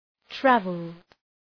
Προφορά
{‘trævəld}